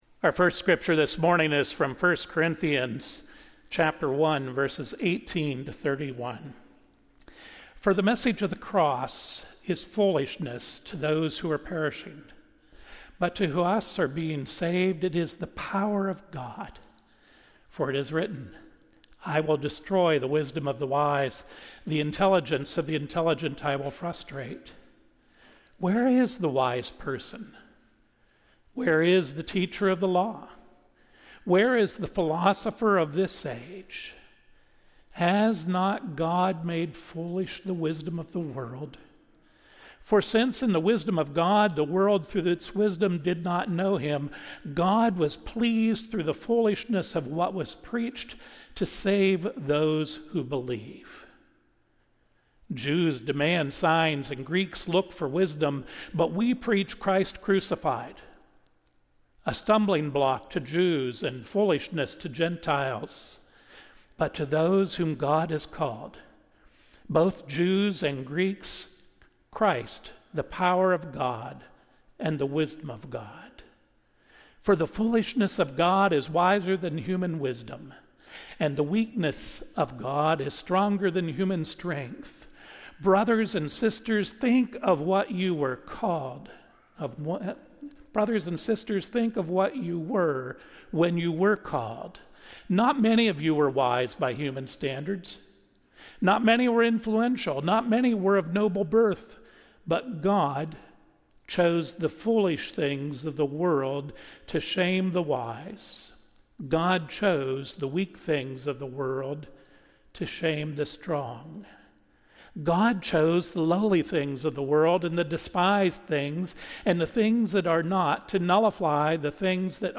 Sermons | Stone UMC